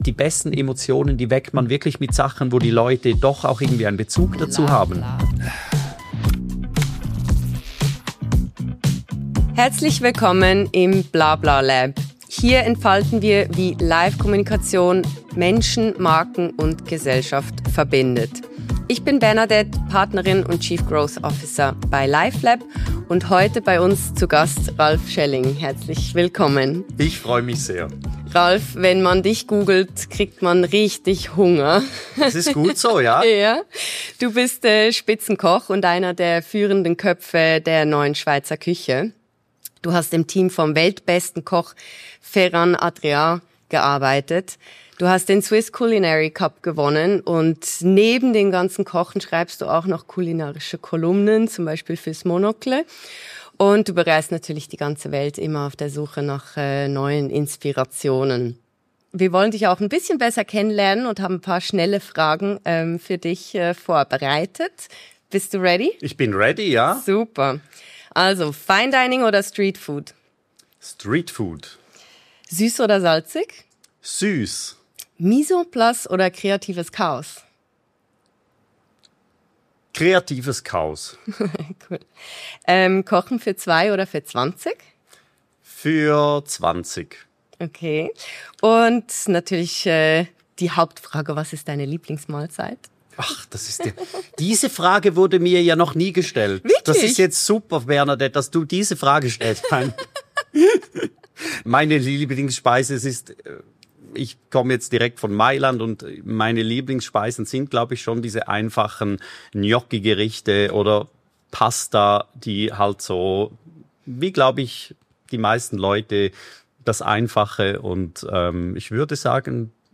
Ein Gespräch über Geschmack, Erinnerung und Nachhaltigkeit.